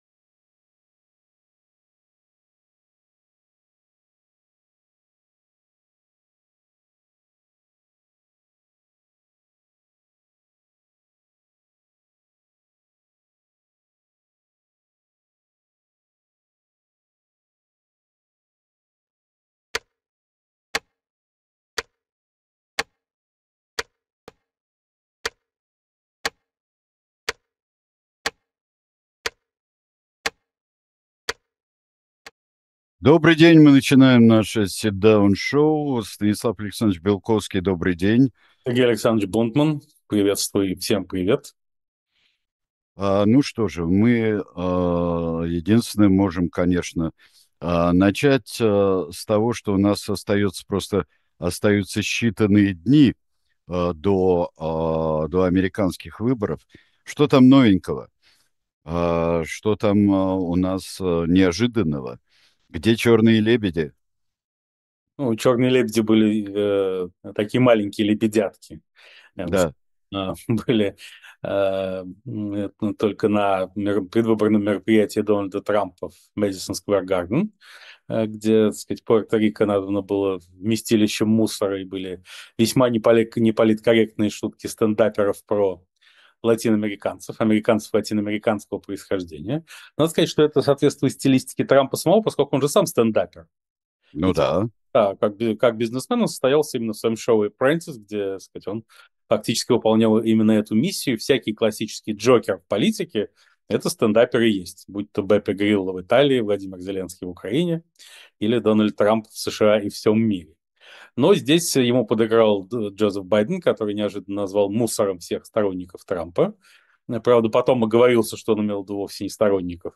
Ведёт эфир Сергей Бунтман